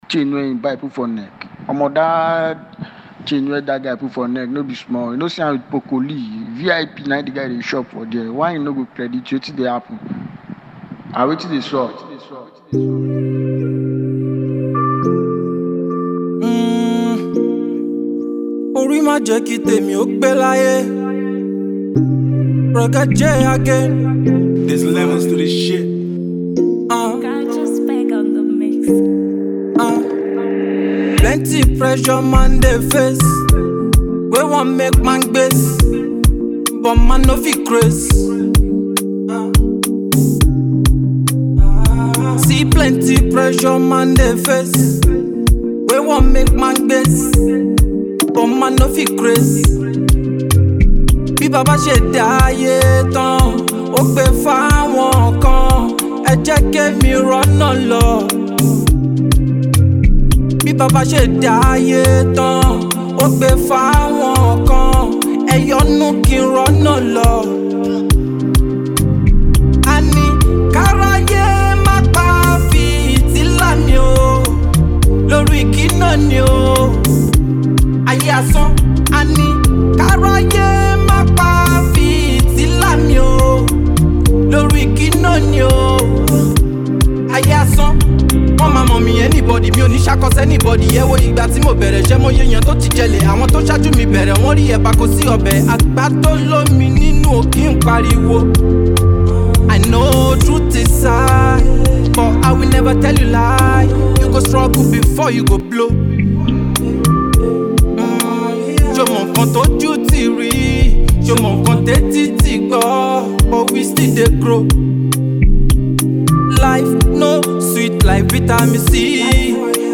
soul-stirring melodies